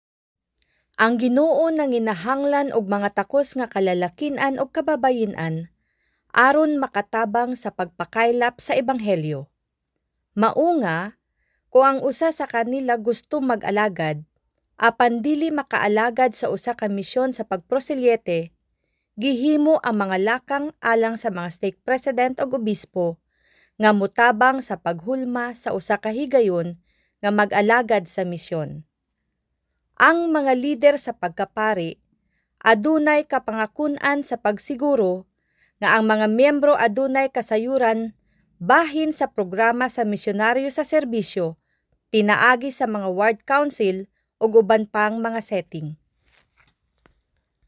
CEBUANO FEMALE VOICES
We use Neumann microphones, Apogee preamps and ProTools HD digital audio workstations for a warm, clean signal path.